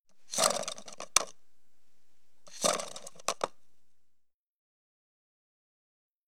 household
Toilet Paper from Roll Spins Around